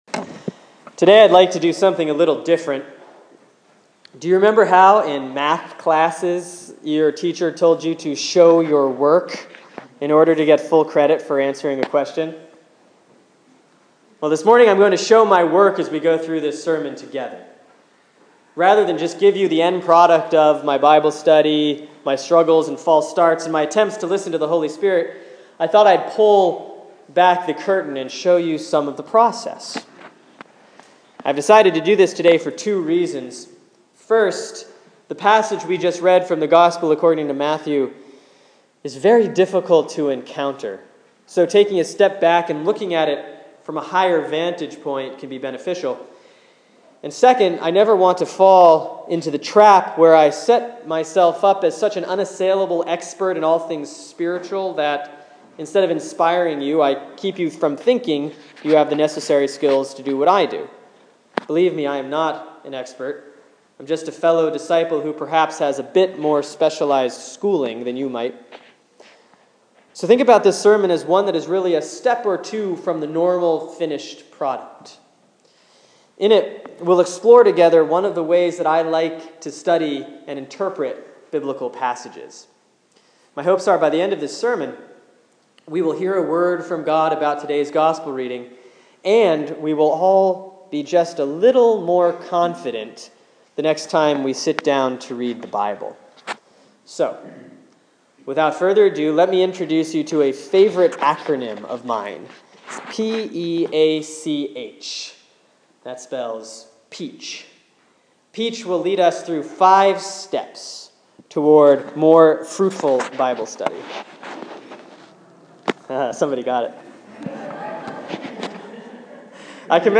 Sermon for Sunday, October 12, 2014 || Proper 23A || Matthew 22:1-14